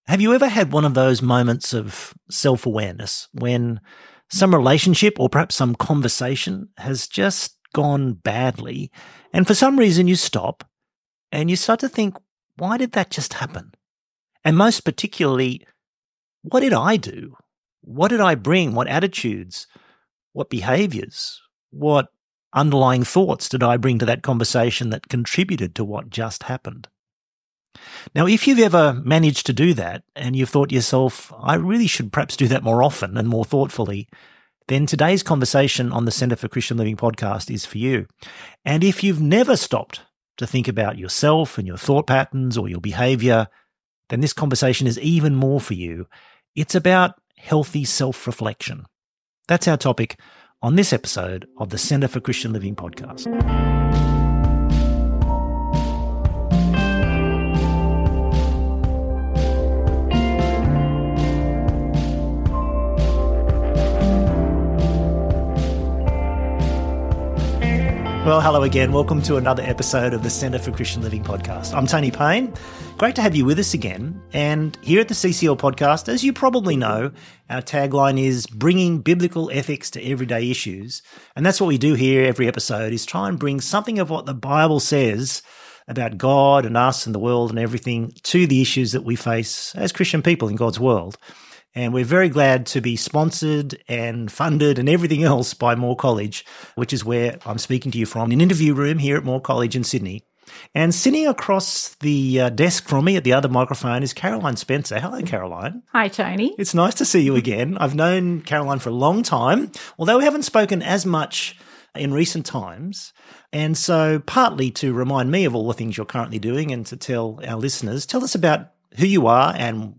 I have a special guest interview today with someone that I have a lot in common with, so I know you will love this conversation.